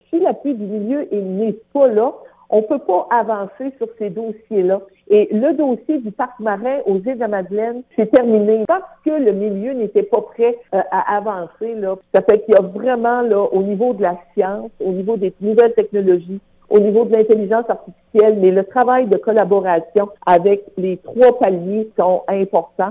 D’ici le scrutin, la salle des nouvelles de CFIM vous présente des entrevues thématiques réalisées chaque semaine avec les candidat(e)s. Les sujets abordés sont les suivants: Environnement (31 mars au 2 avril), Territoire et insularité (7 au 9 avril) et Perspectives économiques (14 au 16 avril).